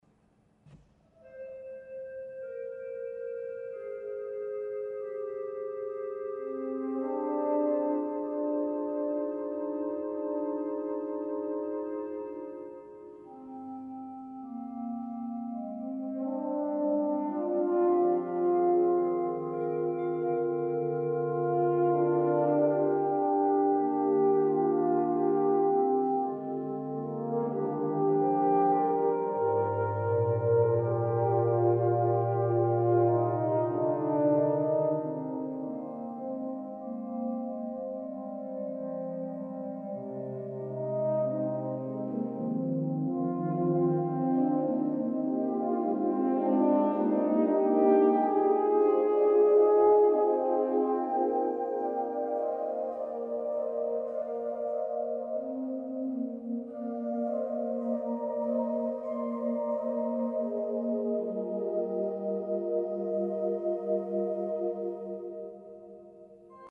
Corno (Trombone) e Organo Horn (trombone) and organ